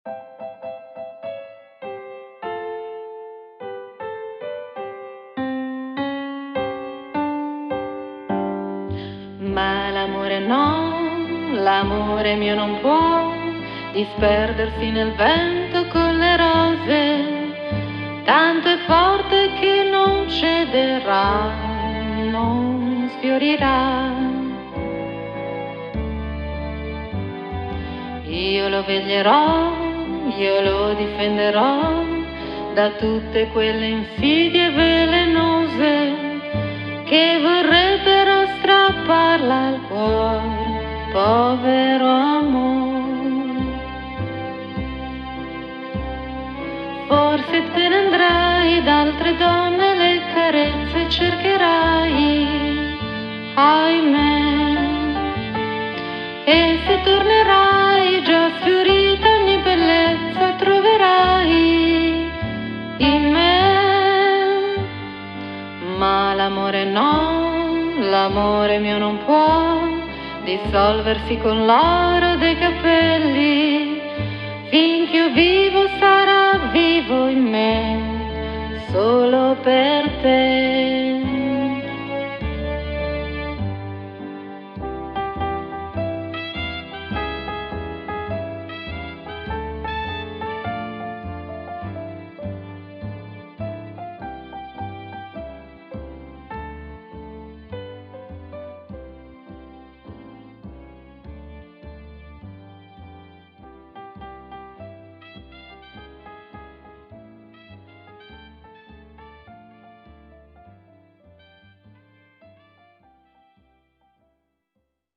La voce femminile